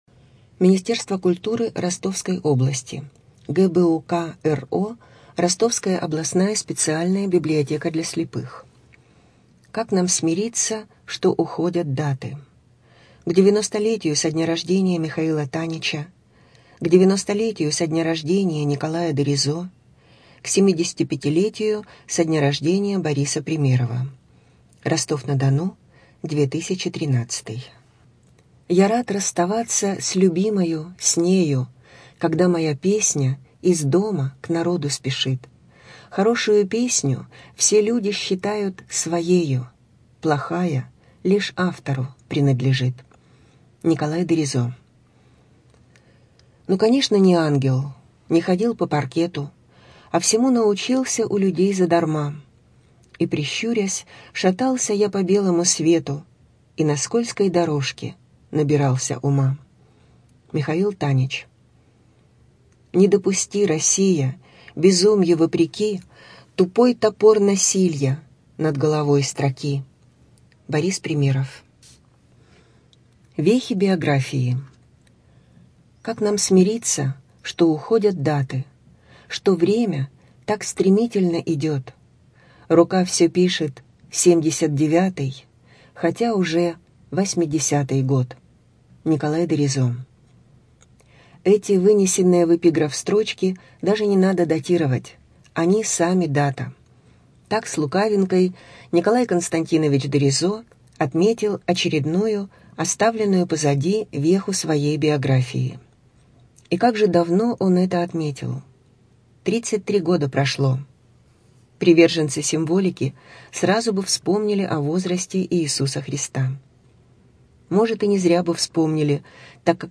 Студия звукозаписиРостовская областная библиотека для слепых